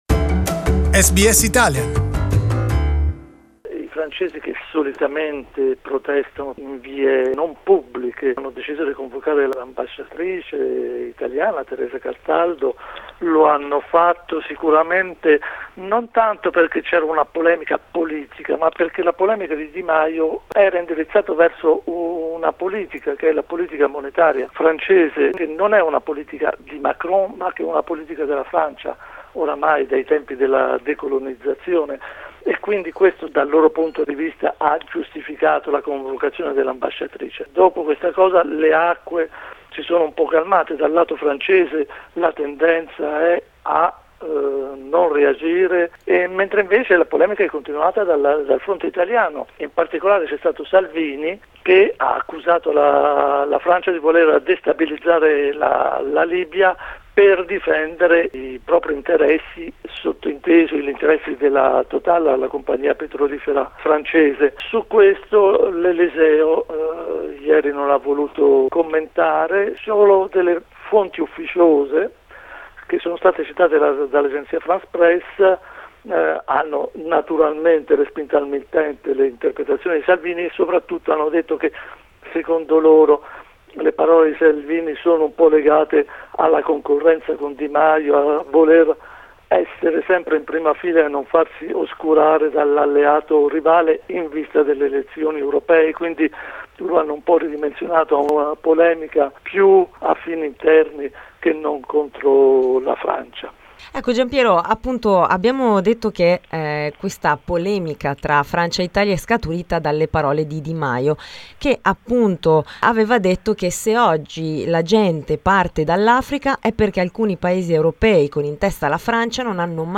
We have talked about the issue with the correspondent from Paris